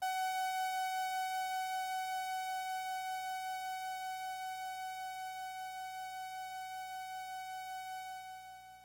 描述：通过Modular Sample从模拟合成器采样的单音。
Tag: F6 MIDI音符-90 Sequntial-MAX 合成器 单票据 多重采样